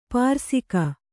♪ pārsika